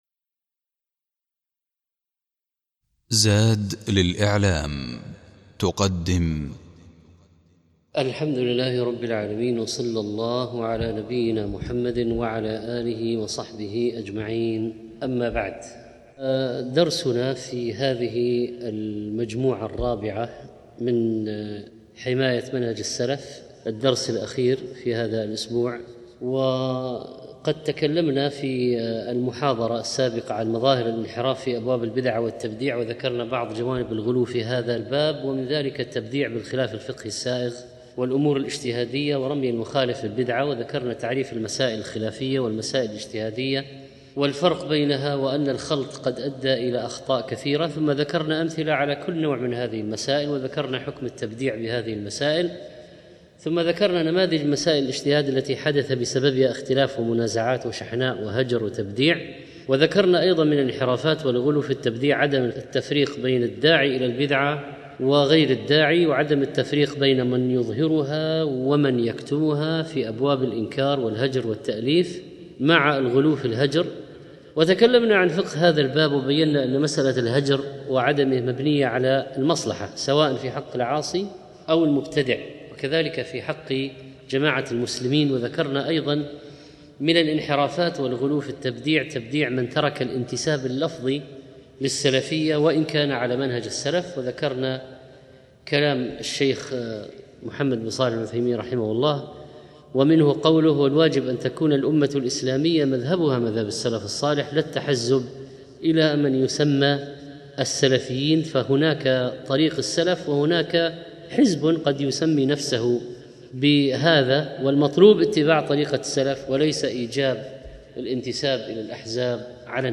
24 شوّال 1435 الزيارات: 13177 تحميل تحميل ملف صوتي تحميل ملف فيديو 56- ضوابط البدعة، والانحرافات في أبواب البدعة والتبديع 8 ذكر الشيخ في هذا الدرس بعض المسائل التي كانت مدخلا لانتشار البدع وتطرق لمسألة المصلحة المرسلة، وبين الشيخ ذلك بضرب الأمثلة، وكيف نفرق بين البدعة والمصلحة المرسلة، وذكر ضوابط وشروط للمصلحة المرسلة، وأورد على ذلك أمثلة كثيرة، كما بين الشيخ الأمور التي تدخل بها المصلحة المرسلة، ومناطات الاشتراك والاختلاف بين المصلحة المرسلة والبدعة، وذكر دور الإمام الشاطبي في ذلك، وما صحة قول: بدعة حسنة وبدعة سيئة.